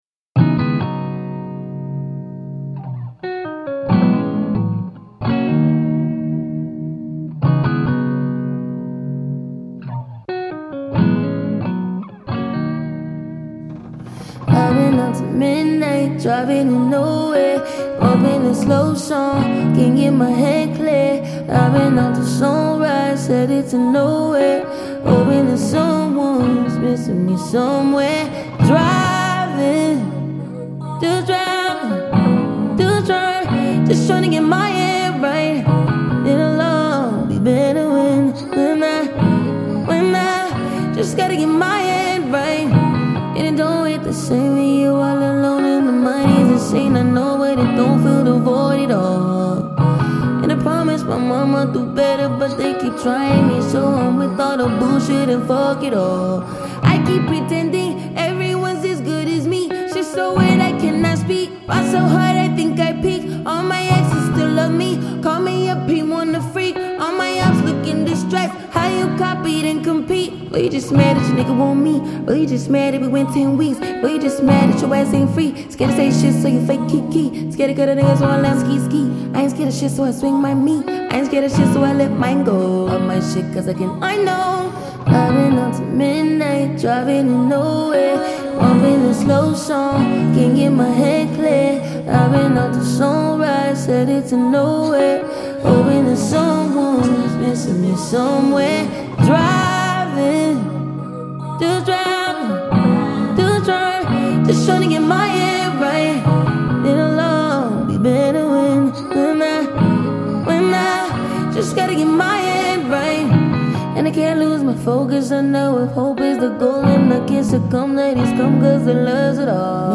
38/100 Genres : french rap Télécharger